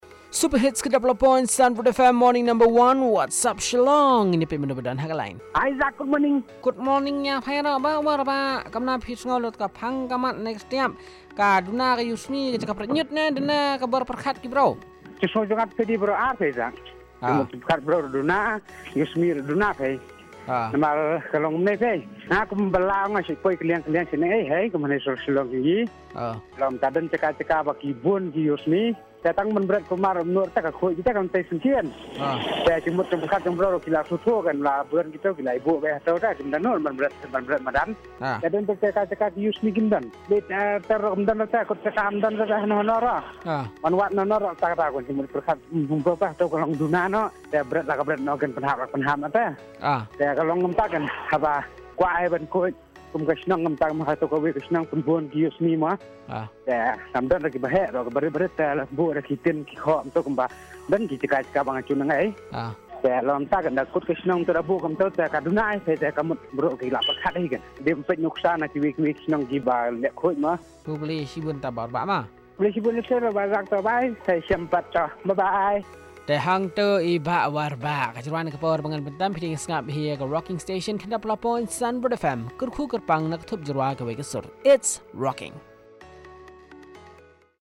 Caller 3 on Littering